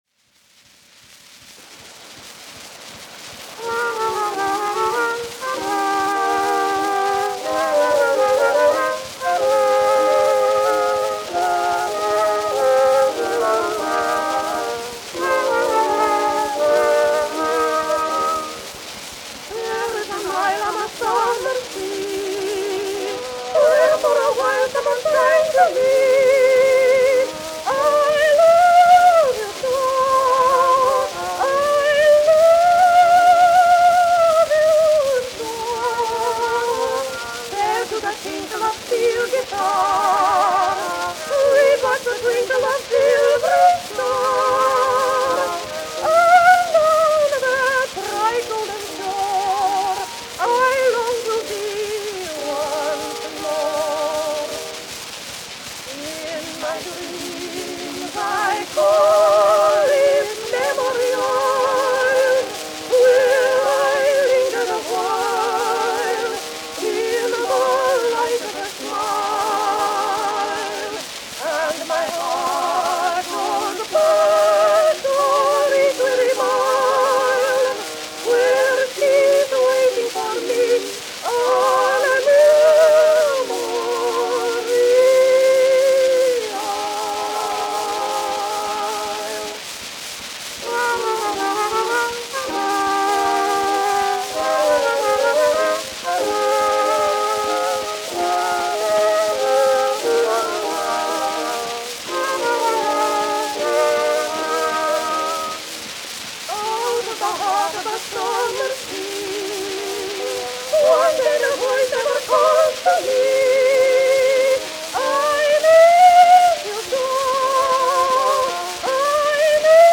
Dubbed from Edison Diamond Disc matrix 9207.
Female voices with orchestra accompaniment.
Popular music—1921-1930.